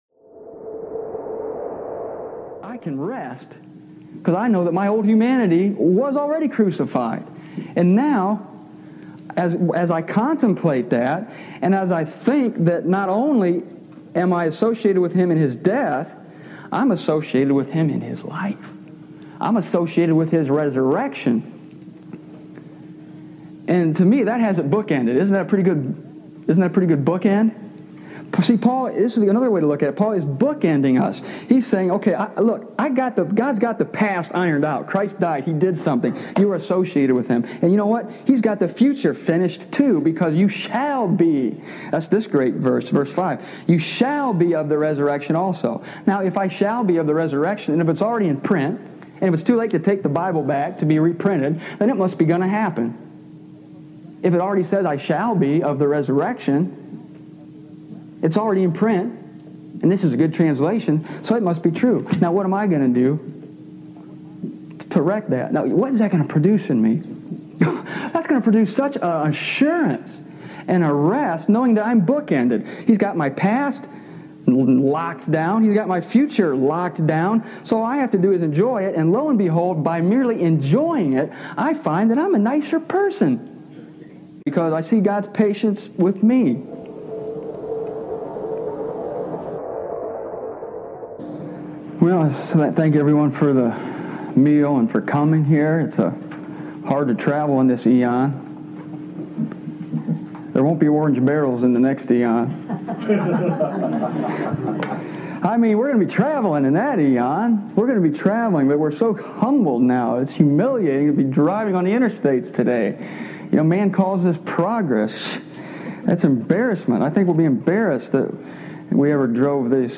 I think the greatest truth in this presentation is that behavior is inspired, not by exhortation, but by revelation, that is, a revelation of who we are in Christ. I recorded this audio in Greenwich, OH , in 1995.